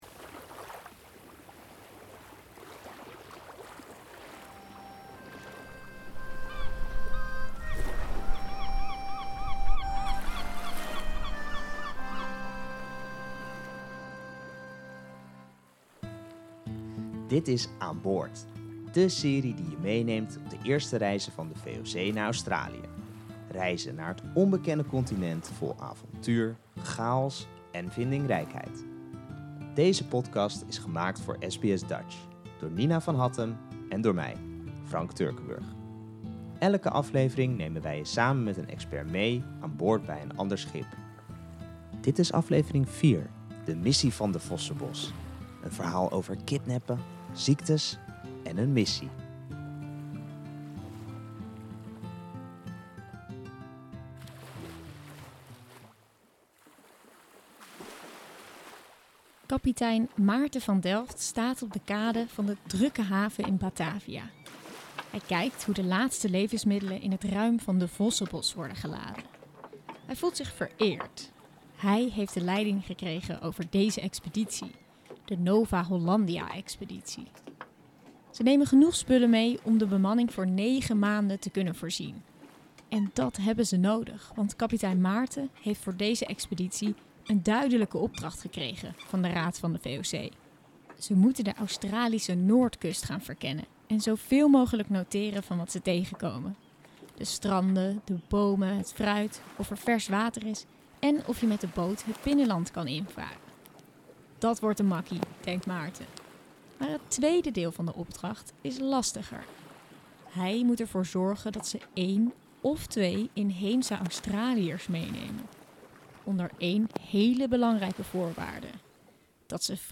Aan Boord is onze podcastserie over de eerste reizen van de VOC naar Australië. Elke aflevering ga je mee met een andere schip en vertelt een expert over het wel en wee op zee.